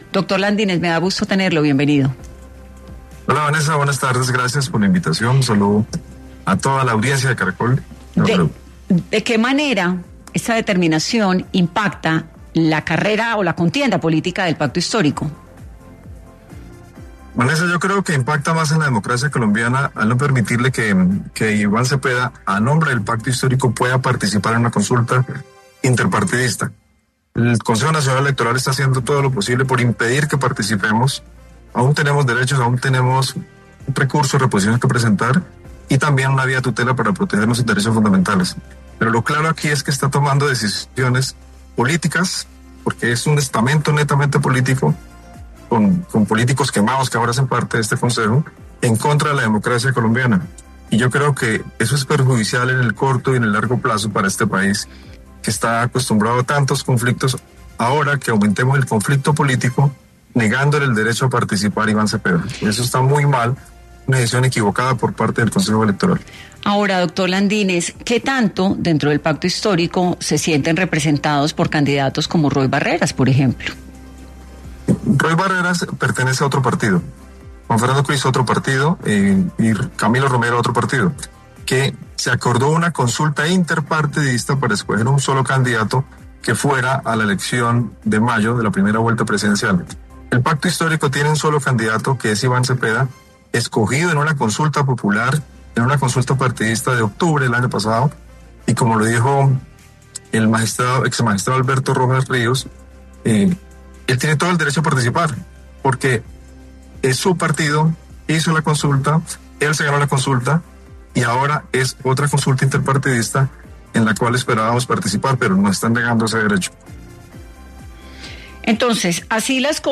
En Dos Puntos de Caracol Radio estuvo Heráclito Landínez, representante a la Cámara del Pacto Histórico quien se refirió a la decisión del CNE